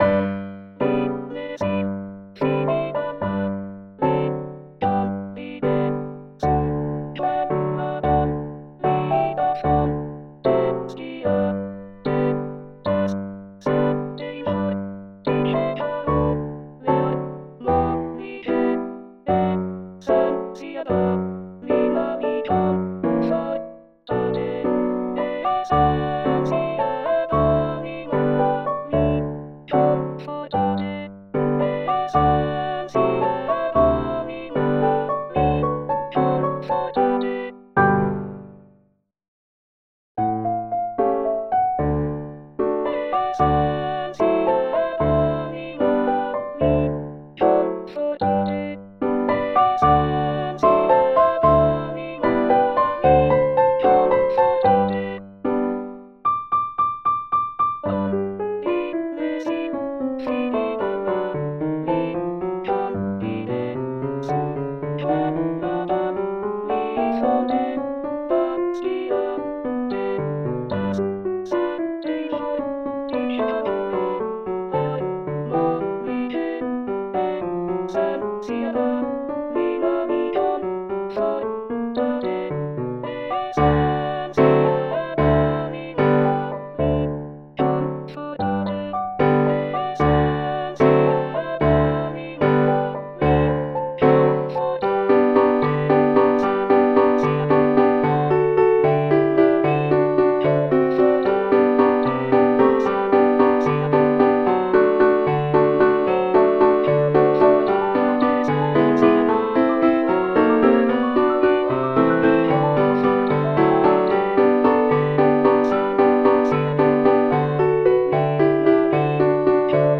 alto-2-coro-e-ballabile-reduction.mp3